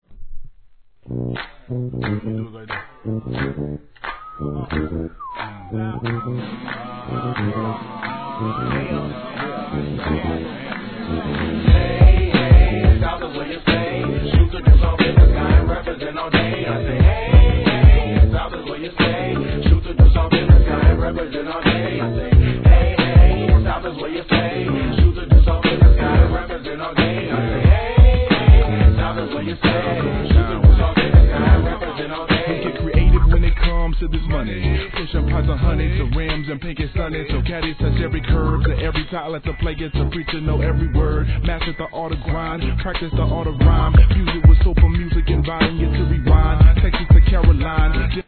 HIP HOP/R&B
JAZZYでムーディーなトラック、そして大人なRAPとソウルフルなコーラス♪